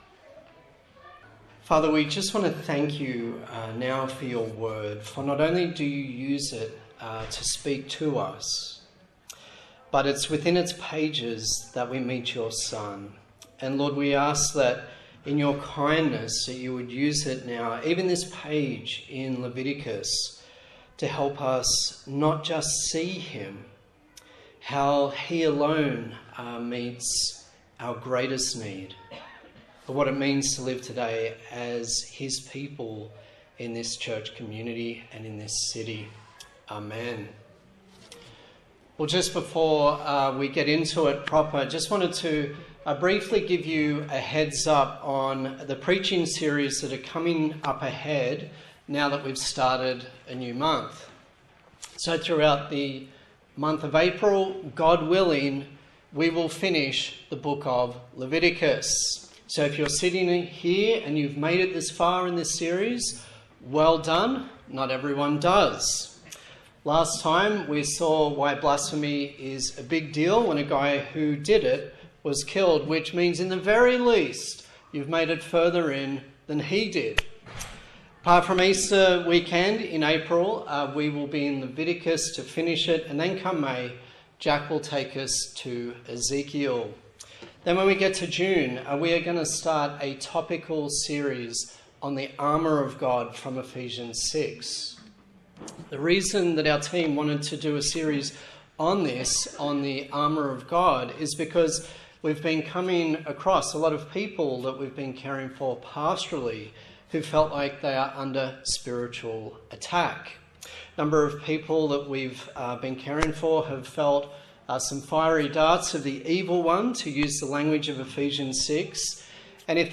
A sermon in the series on the book of Leviticus
Passage: Leviticus 25 Service Type: Morning Service